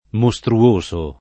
[ mo S tru- 1S o ]